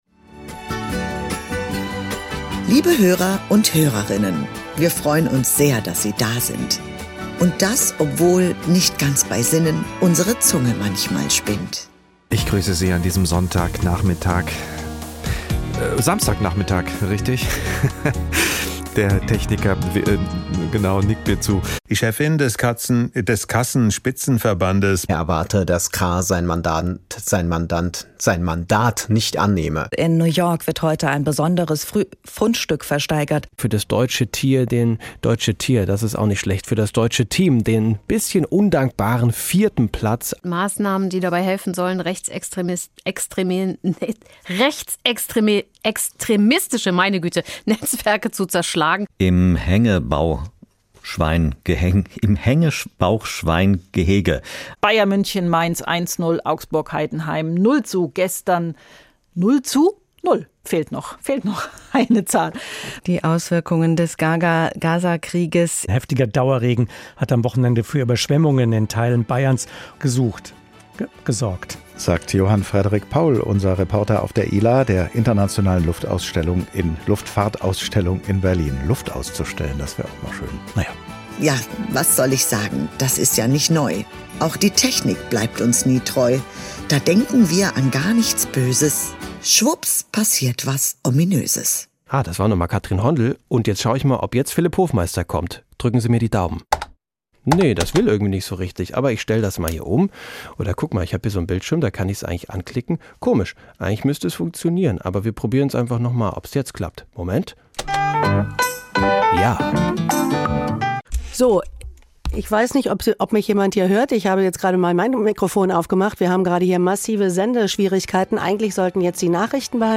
hr INFO - Best of Versprecher und Pannen 2024 (Teil 1)